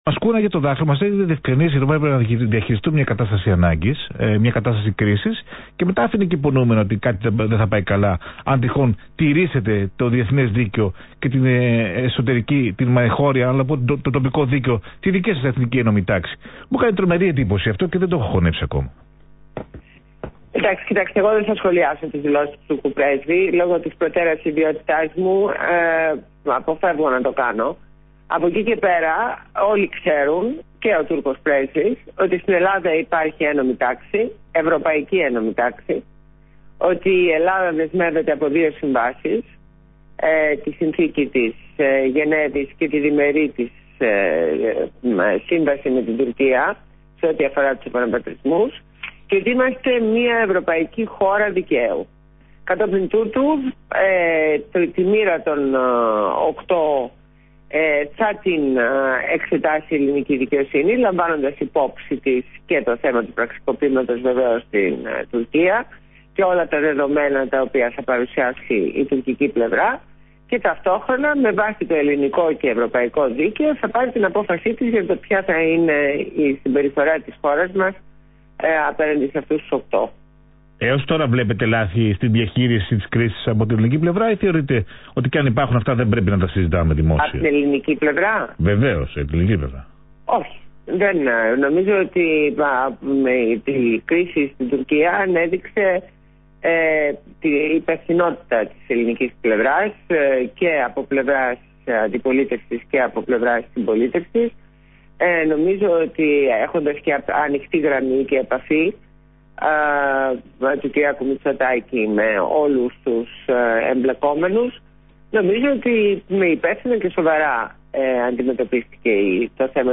Συνέντευξη στο ραδιόφωνο του ALPHA 98,9fm